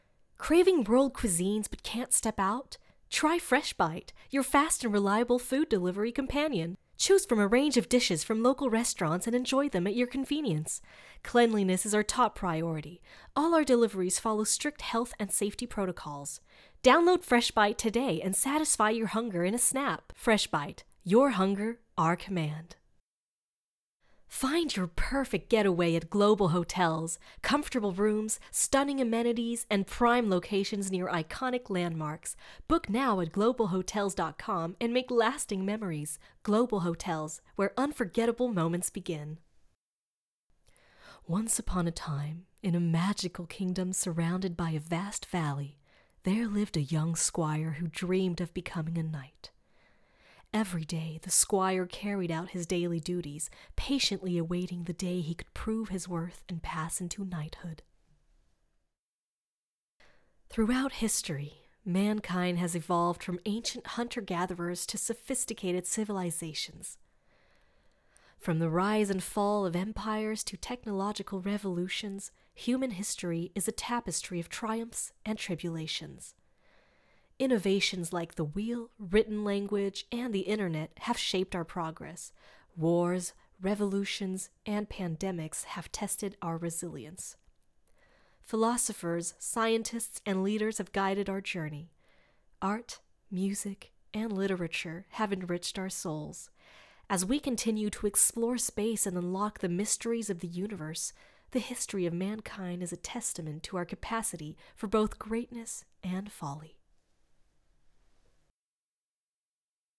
démo voix off français